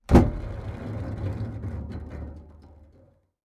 Metal_03.wav